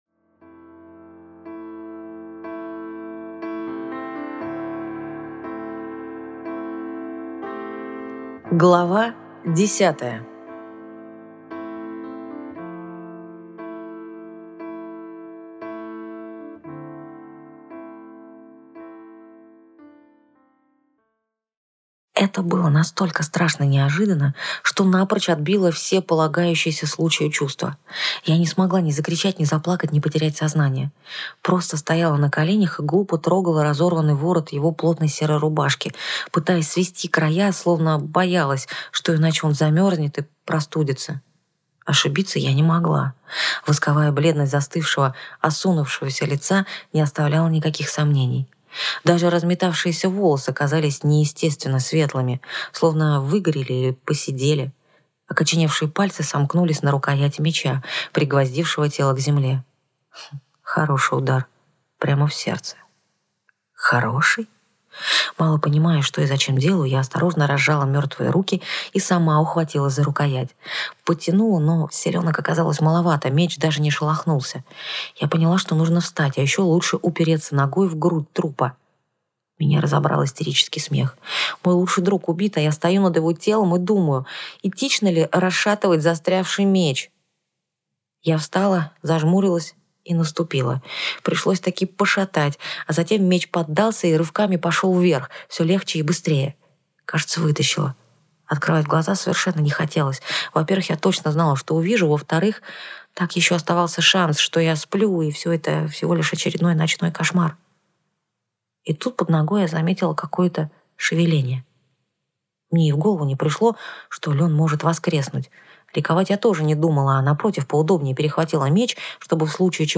Аудиокнига Ведьма-хранительница - купить, скачать и слушать онлайн | КнигоПоиск